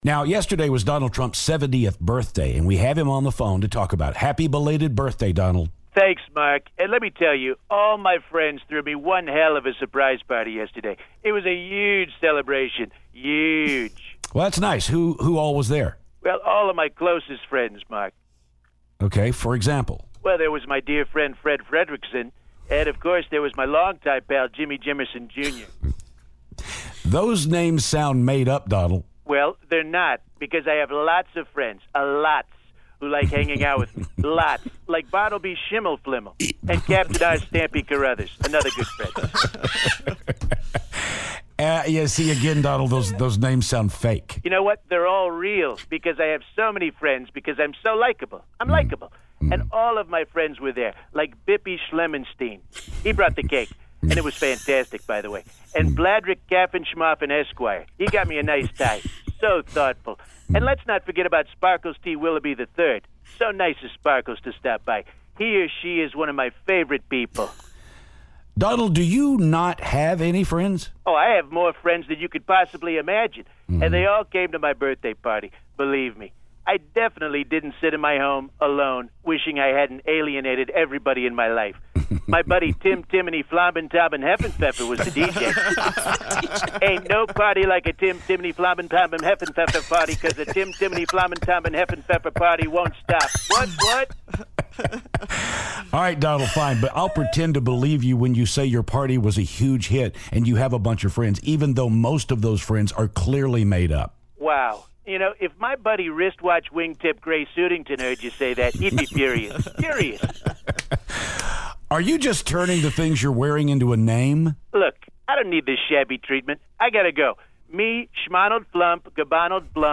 Donald Trump calls to talk about what he did on his birthday.